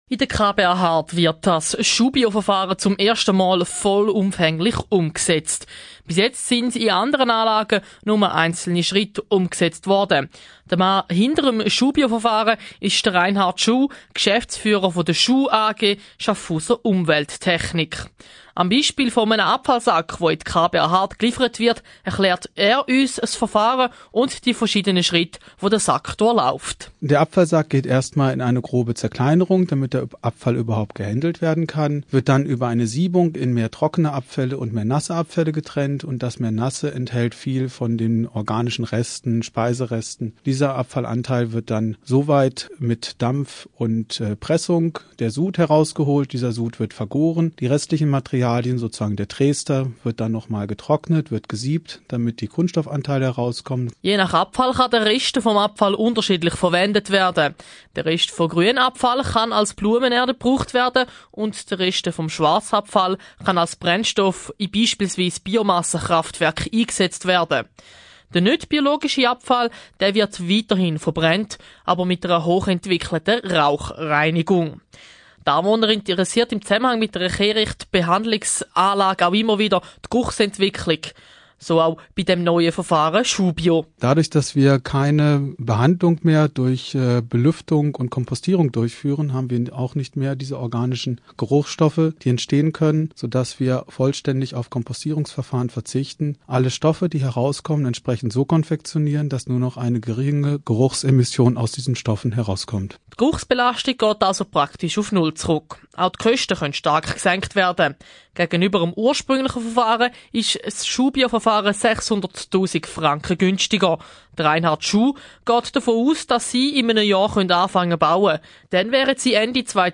Radio Munot - Interview vom 18.12.2008